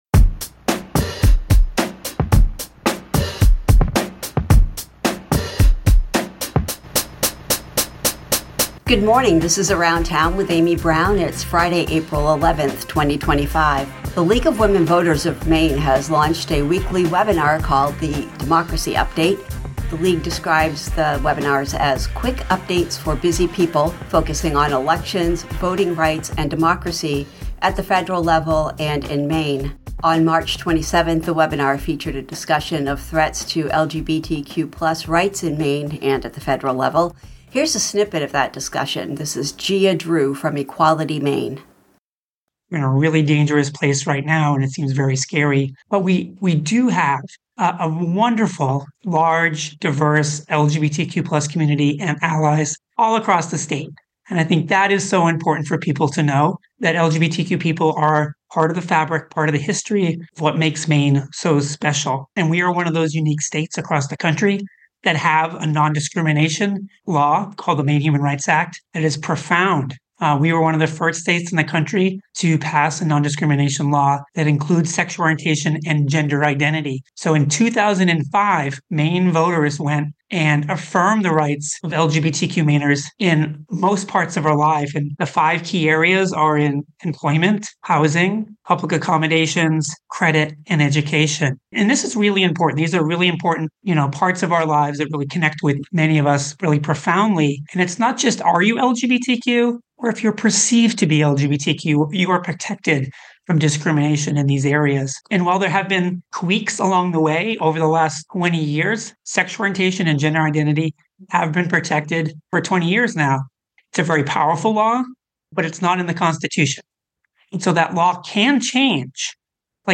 On March 27th the webinar featured a discussion of threats to LGBTQ+ rights. Here’s a snippet of that discussion.